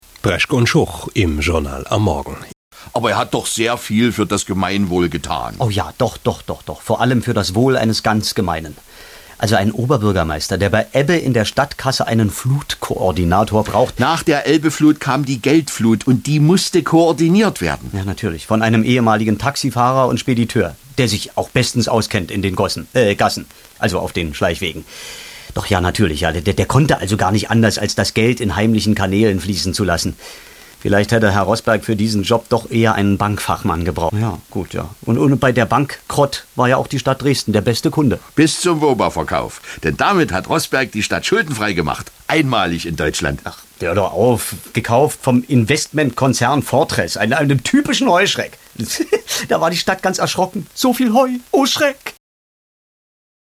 Geschichten, Kabarettszenen, Parodien und Minihörspiele.